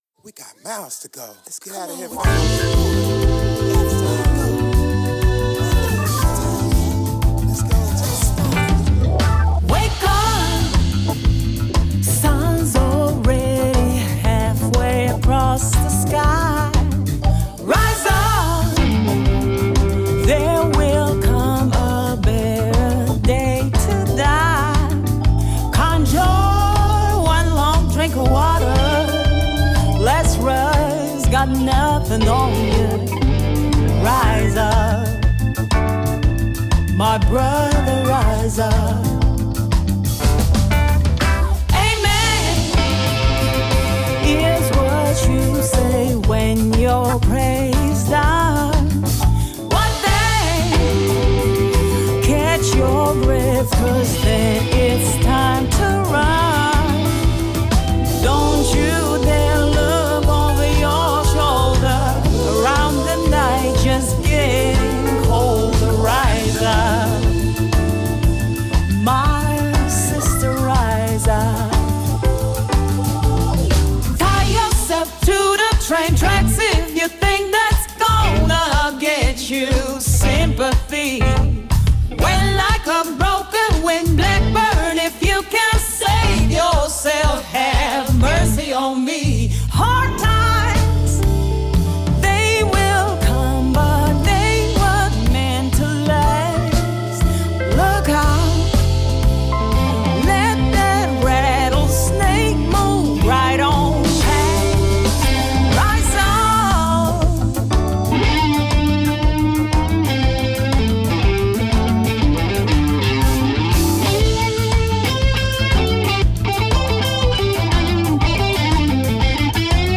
Programa de radio sobre blues con noticias y comentarios emitido en UPVRadio y Bittorrent